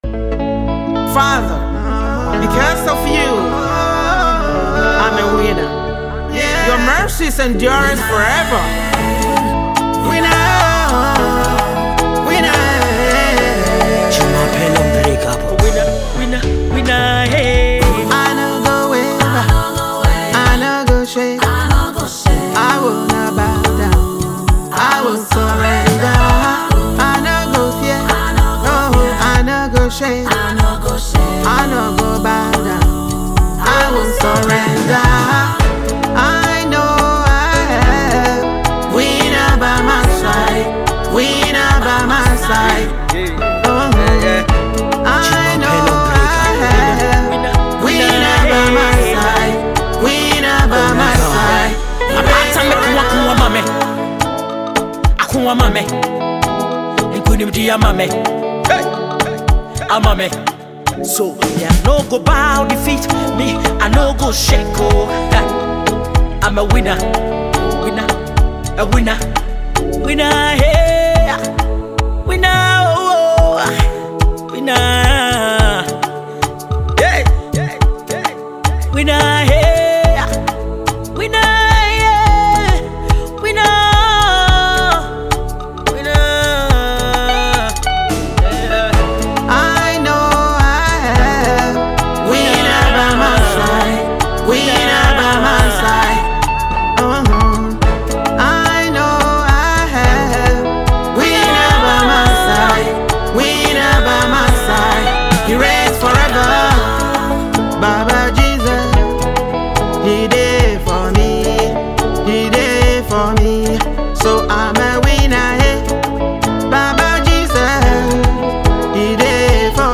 uplifting and spirit-filled anthem
With inspiring lyrics and soul-stirring vocals
blends contemporary gospel with Afro rhythms
Ghana Gospel Music